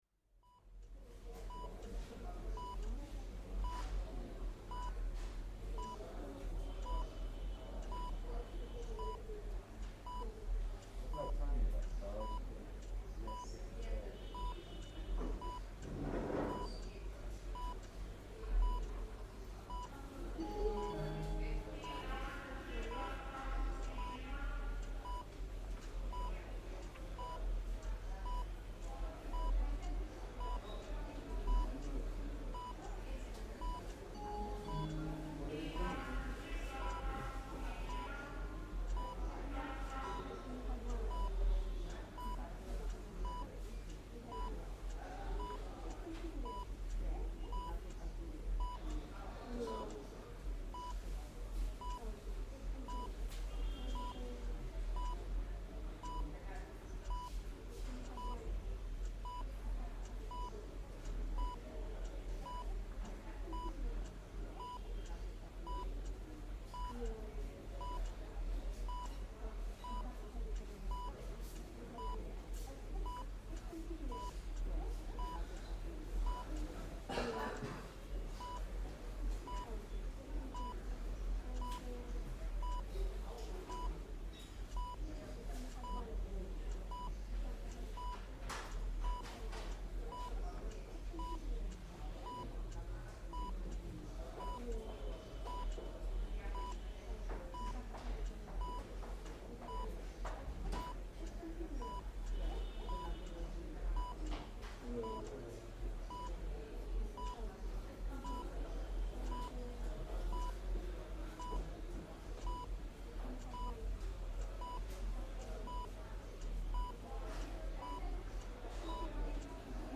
دانلود آهنگ محیط سالن انتظار بیمارستان از افکت صوتی طبیعت و محیط
دانلود صدای محیط سالن انتظار بیمارستان از ساعد نیوز با لینک مستقیم و کیفیت بالا
جلوه های صوتی